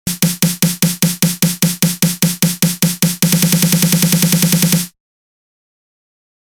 まずはオートメーションを何も設定していない素の状態のSnareです。
当たり前ですが、ひたすらSnareがなっている状態ですね。笑
このままでも積み上がっていくサウンドを表現できていますが、なんだか味気ないです。